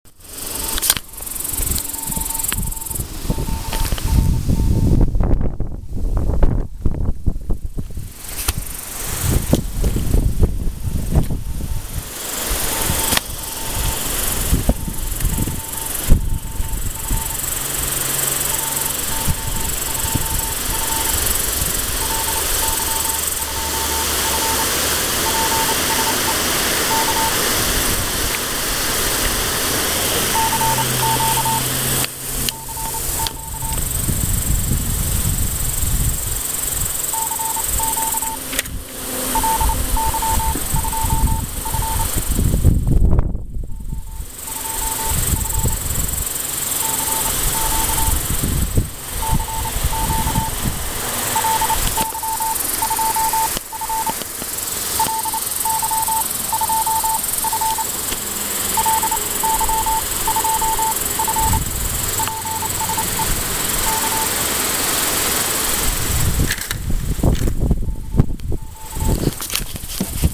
В обед выполнил радио- выбег, интересно было послушать в лесах- полях
который был весьма сильный.